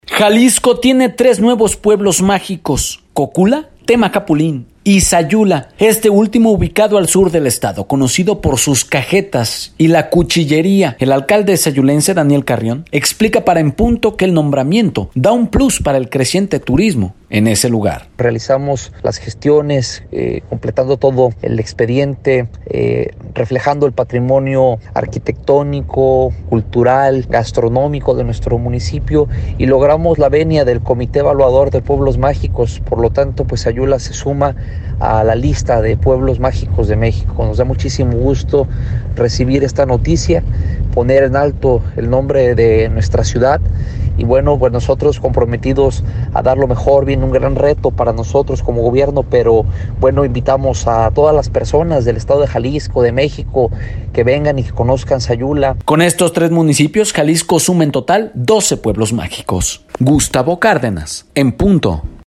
Jalisco tiene tres nuevos pueblos mágicos, Cocula, Temacapulin y Sayula, este último al sur del Estado, conocido por su cajeta y cuchillería. El alcalde sayulense, Daniel Carrión informó para el programa En Punto que el nombramiento da un plus para el creciente turismo en el lugar.